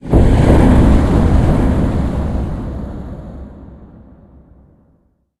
Cri de Gorythmic Gigamax dans Pokémon HOME.
Cri_0812_Gigamax_HOME.ogg